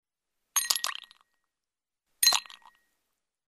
Звуки кубиков льда
Звук падающих кубиков льда в стакан